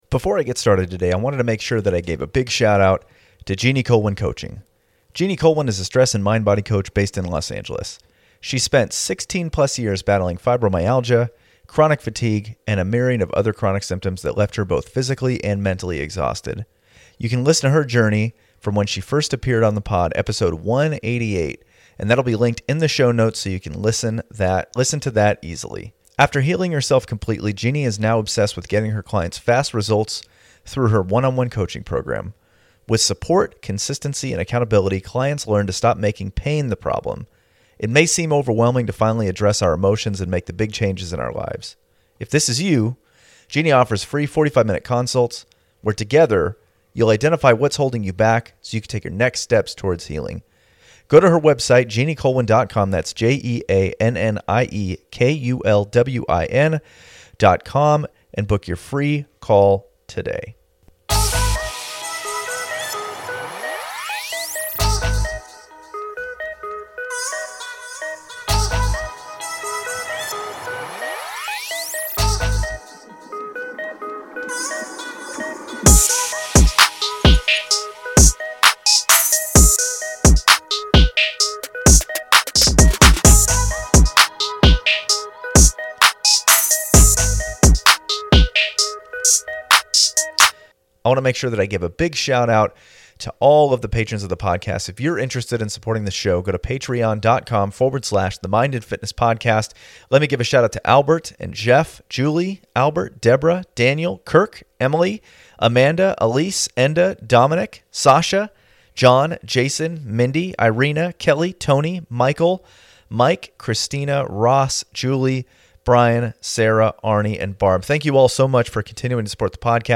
This interview is pretty awesome.